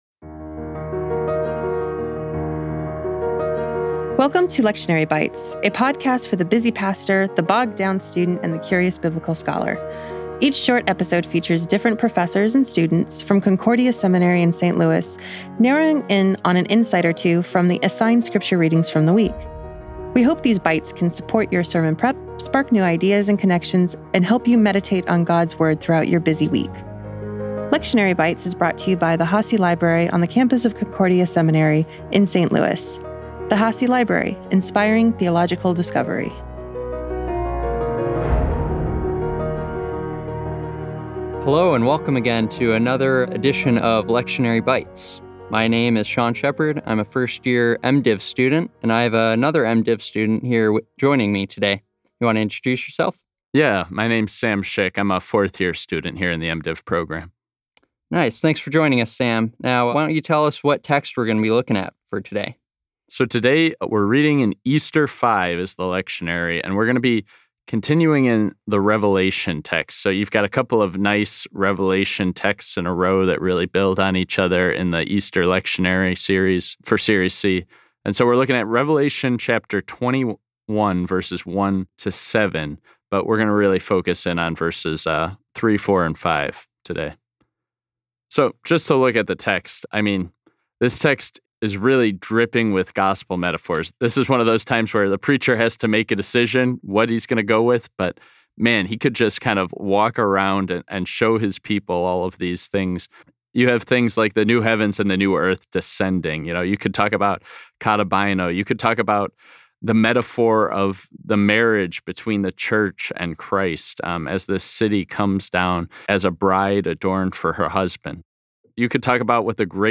Download File of 001. Opening Service (video/mp4, 213.3 MB)